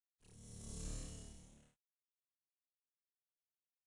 Singing transformer
描述：A singing transformer. Ain't it a somewhat boring one? Well, a transformer has found a few basic frequencies in the place he works, he made the best of it.
标签： songs transformers electronics
声道立体声